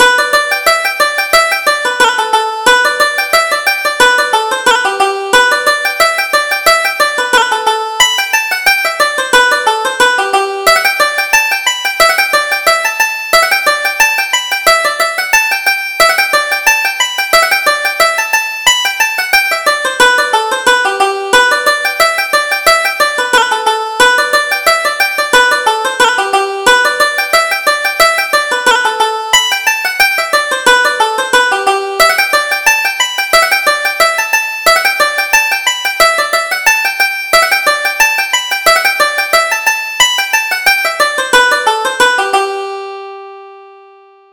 Reel: Within a MIle of Clonbur